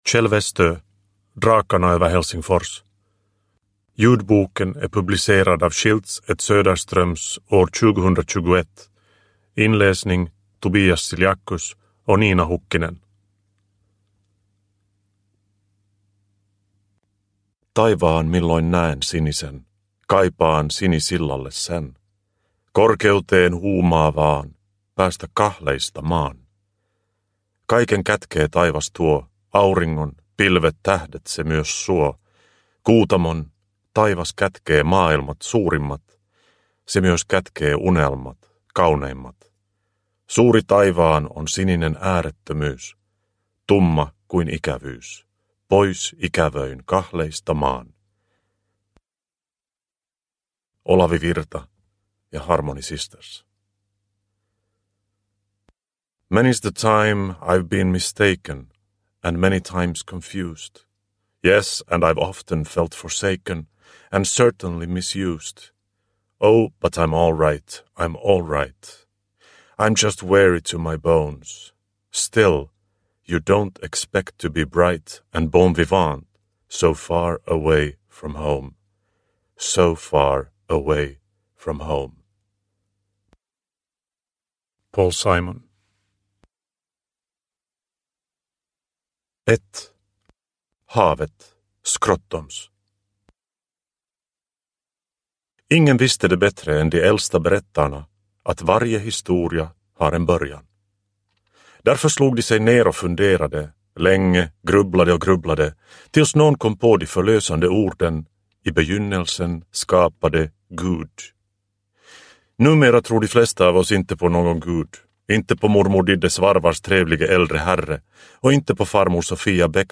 Drakarna över Helsingfors – Ljudbok – Laddas ner